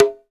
CONGA2L MPC.wav